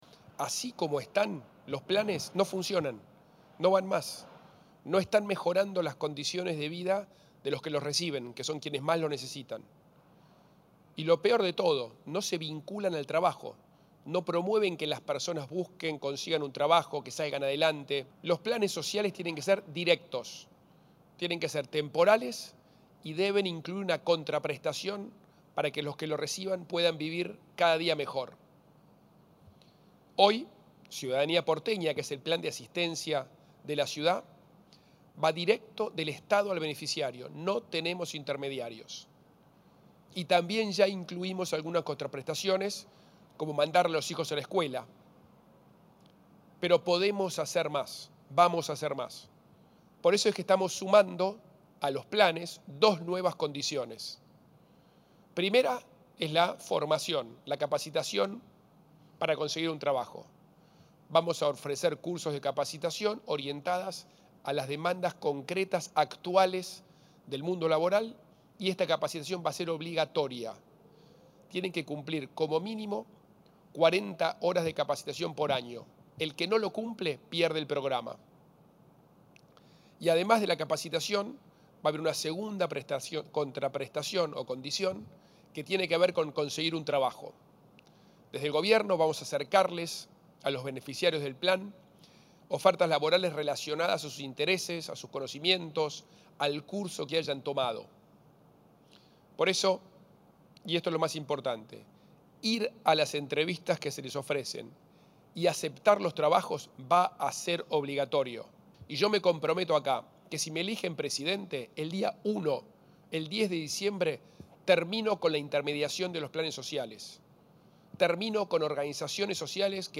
El precandidato a presidente, Horacio Rodríguez Larreta, anunció hoy medidas para brindarles opciones laborales a los beneficiarios del plan social “Ciudadanía Porteña” que entrega la Ciudad de Buenos Aires.
Anuncio-Rodriguez-Larreta.mp3